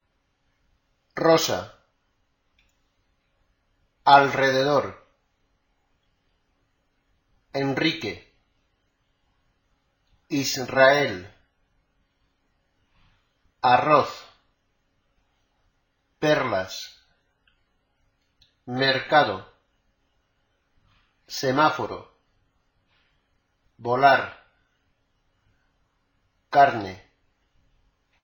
Escucha la pronunciación de las siguientes palabras que incluyen r o rr y marca aquéllas en que escuches el sonido más fuerte.